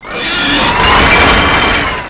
m134_shoot_start.wav